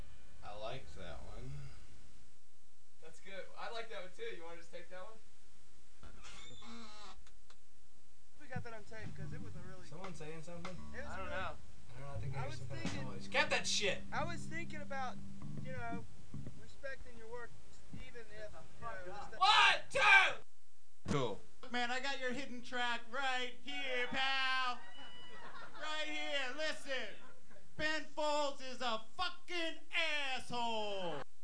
There were two pressings of Whatever and ever Amen. The first had talking in between the tracks and a hidden 'track' at the end.
Most people agree that it was recorded at a show, but opinions on which show it was differ.
The crowd (that's C) thought he was pretty funny.